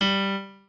pianoadrib1_1.ogg